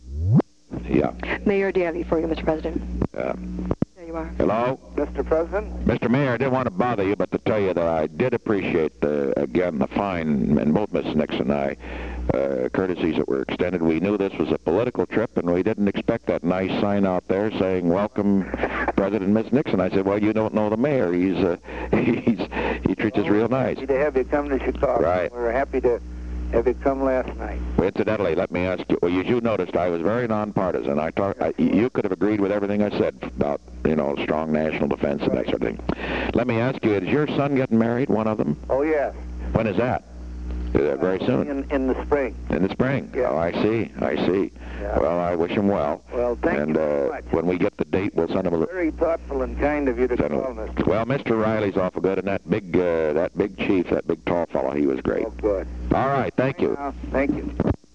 Secret White House Tapes
Location: White House Telephone
The President talked with the White House operator.
The President talked with Daley.